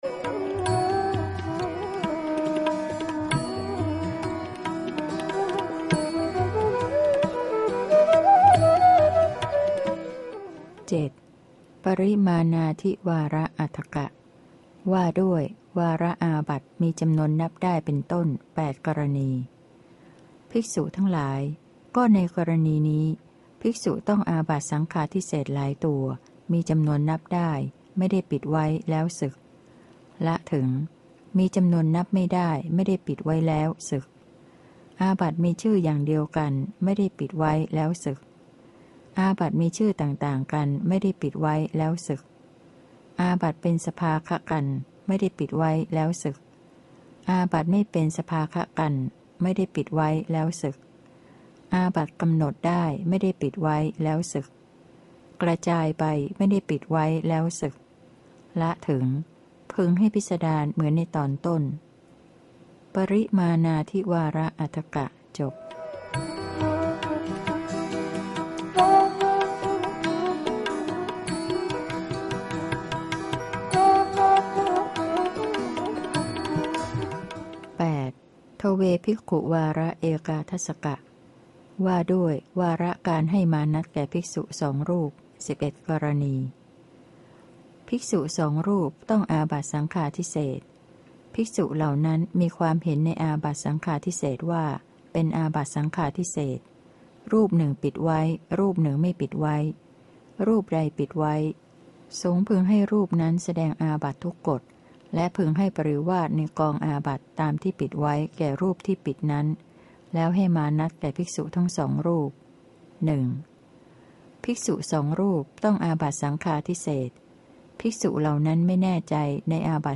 พระไตรปิฎก ภาคเสียงอ่าน ฉบับมหาจุฬาลงกรณราชวิทยาลัย - พระวินัยปิฎก เล่มที่ ๖